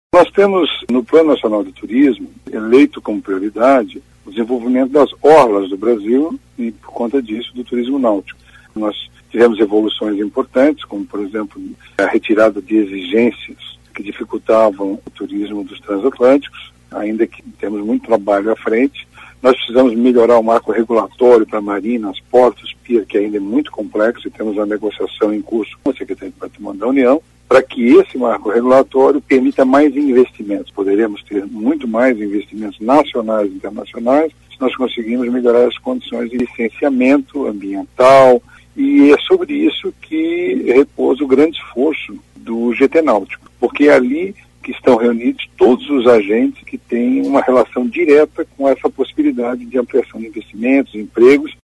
aqui para ouvir declaração do secretário Vinícius Lummertz sobre a importância das discussões no GTT-Náutico.